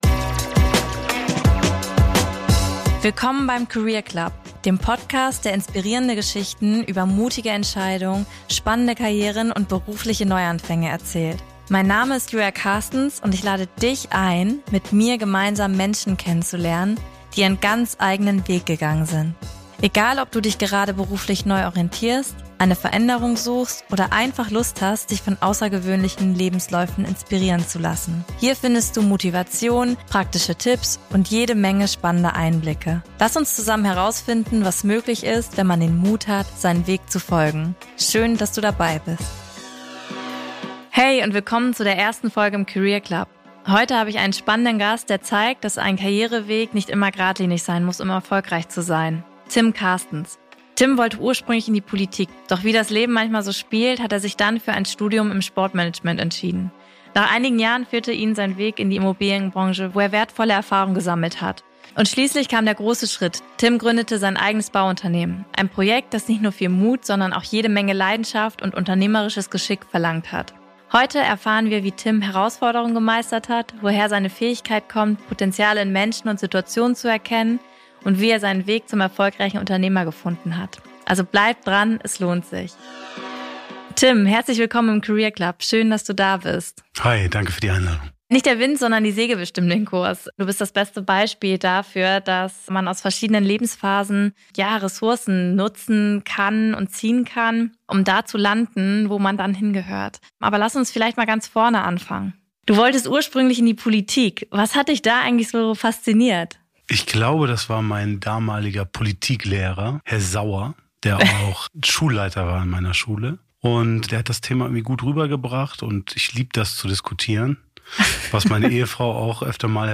Ein Gespräch über Umwege, die sich lohnen, und den Mut, seinen eigenen Weg zu gehen.